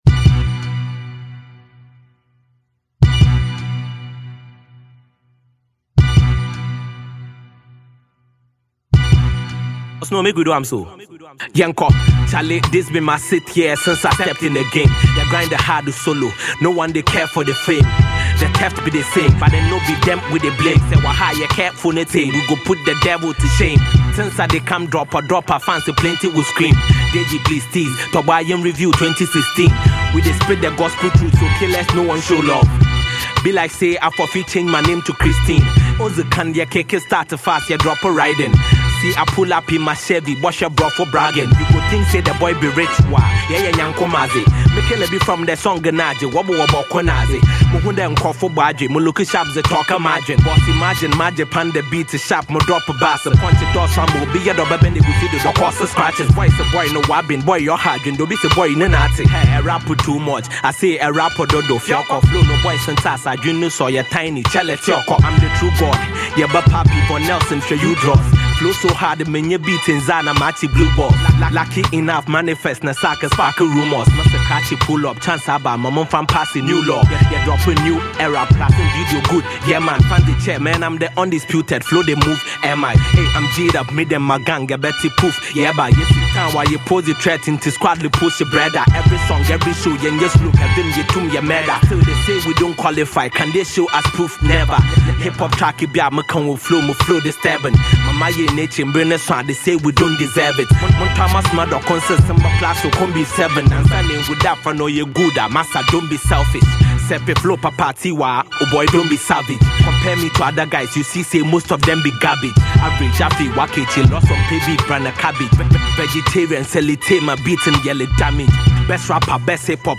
He spits some HOT bars on this one!!!